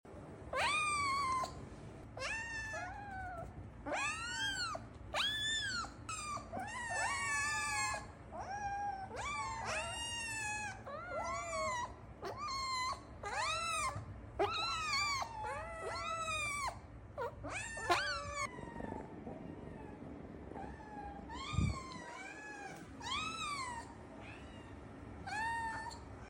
Abyssinian kittens talking… or screaming sound effects free download